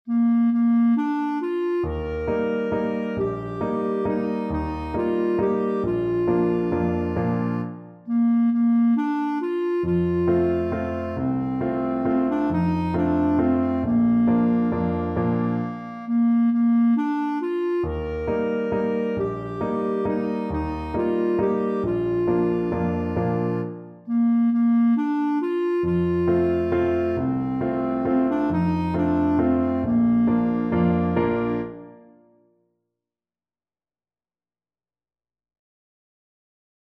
One in a bar .=c.45
3/4 (View more 3/4 Music)
Bb4-Bb5